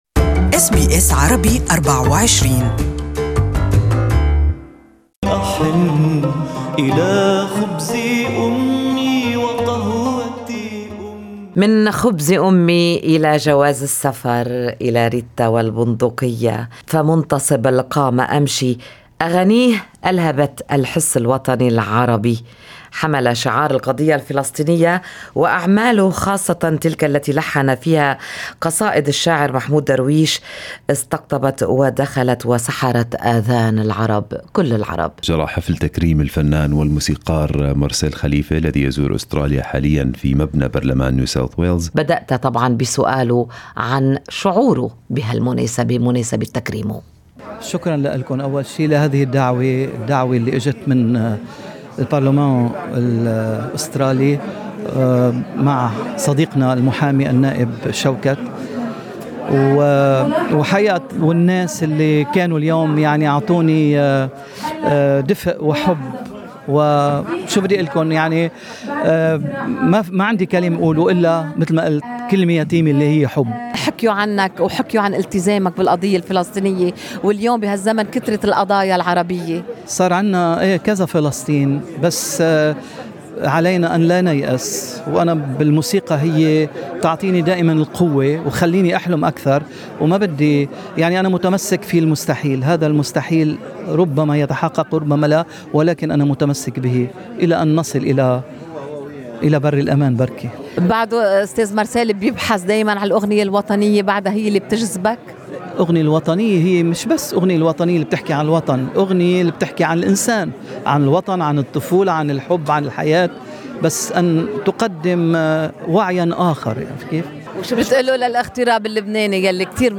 Renowned Lebanese composer, oud master and singer Marcel Khalife spoke to SBS Arabic24 during a special ceremony held at NSW parliament on February 14 to honor his legacy and contribution to the musical heritage of the Arab world.